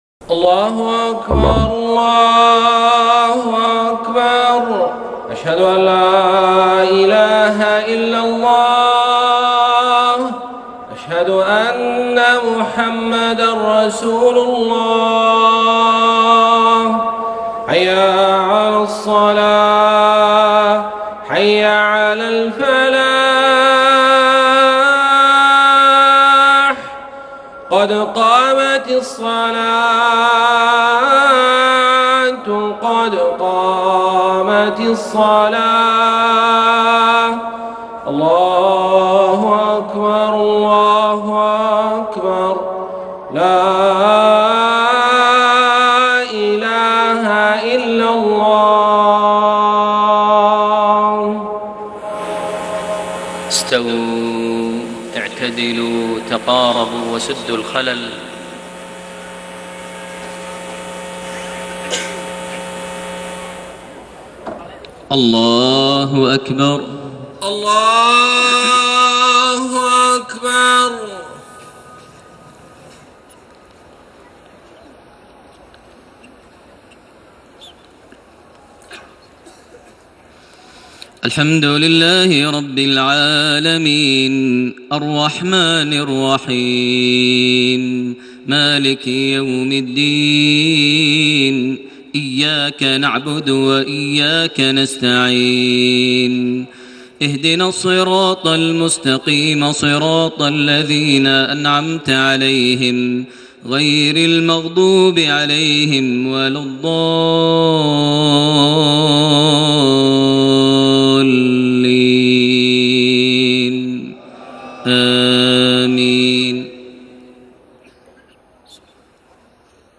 صلاة المغرب 4 شوال 1432هـ سورة الانفطار > 1432 هـ > الفروض - تلاوات ماهر المعيقلي